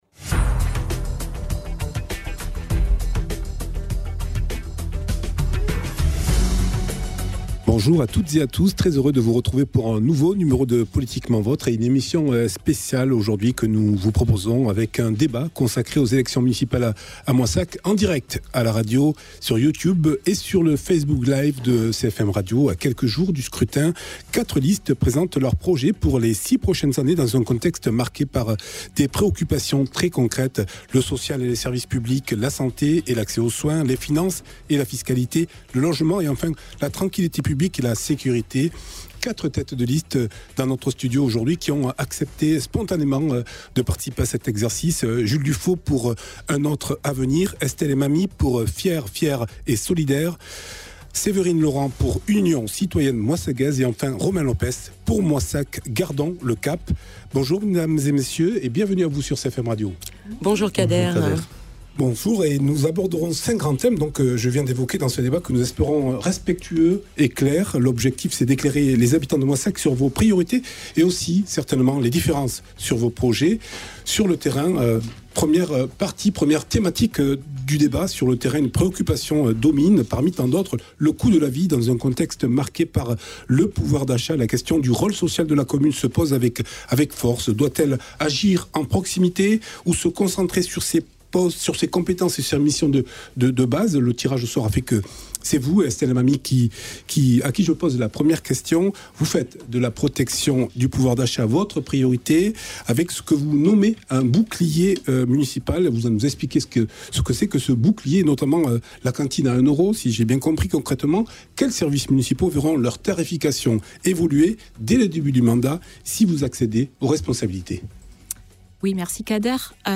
pour un débat d’une heure dans le cadre de la campagne des municipales de Moissac en Tarn-et-Garonne. à la radio: Moissac 90.7 - Montauban101.2 en live sur Youtube et Facebook